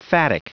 Prononciation du mot phatic en anglais (fichier audio)
Prononciation du mot : phatic